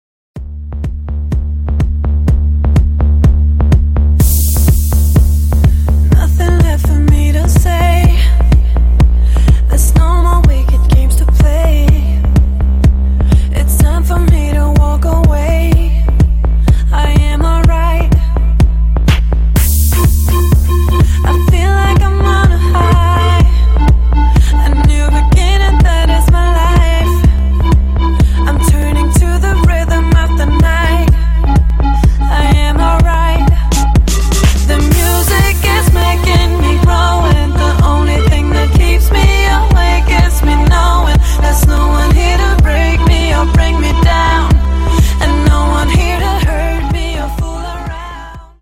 • Качество: 128, Stereo
женский вокал
Electropop